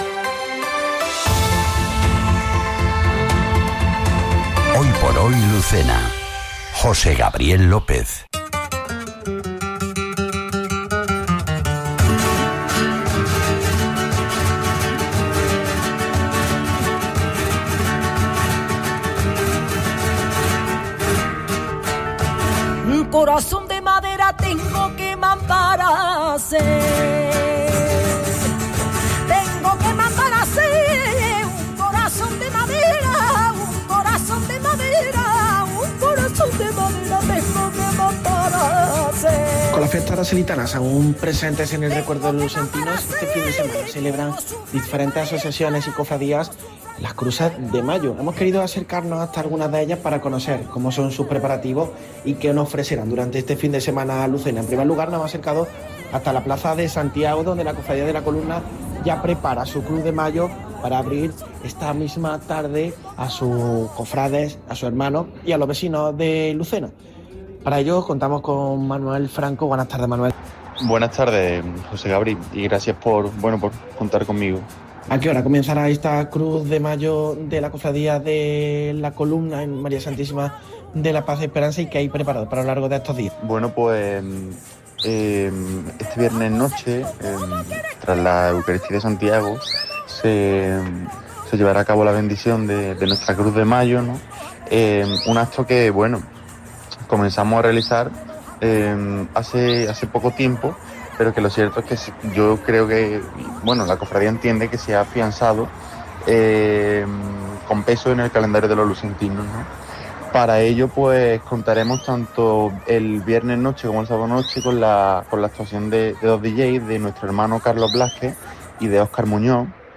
Reportaje Cruces de Mayo Lucena- Viernes 10 de mayo
informativos